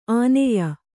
♪ āneya